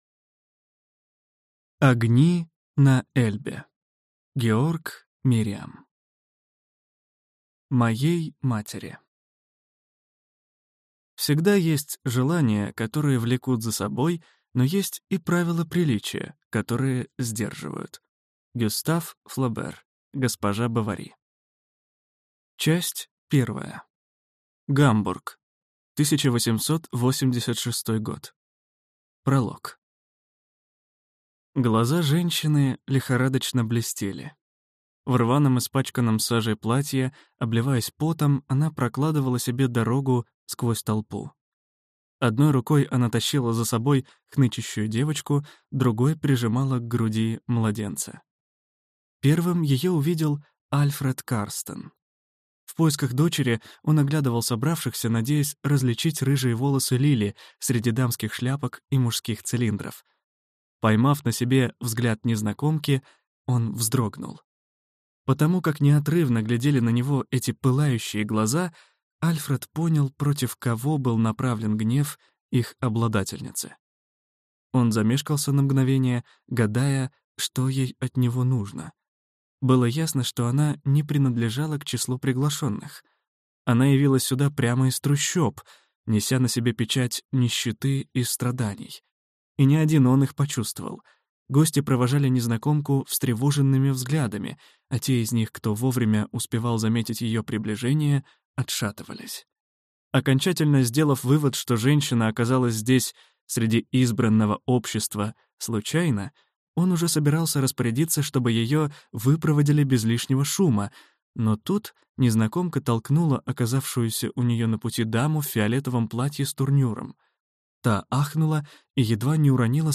Аудиокнига Огни на Эльбе | Библиотека аудиокниг